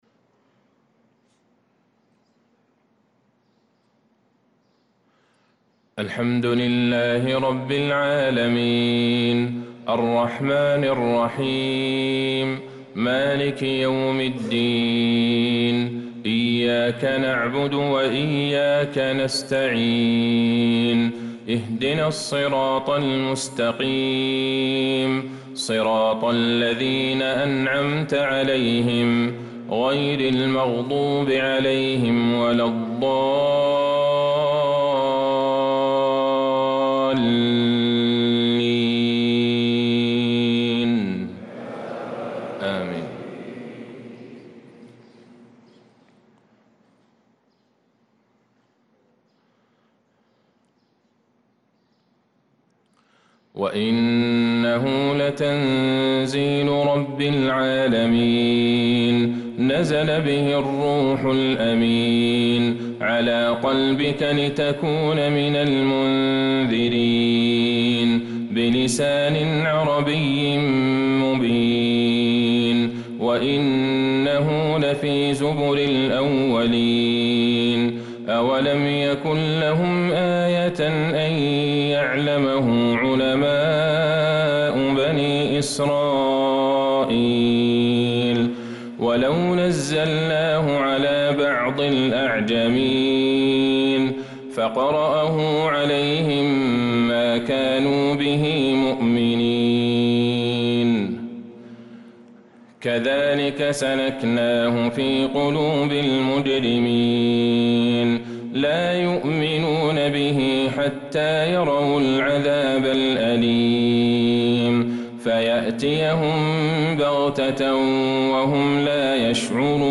صلاة الفجر للقارئ عبدالله البعيجان 20 شوال 1445 هـ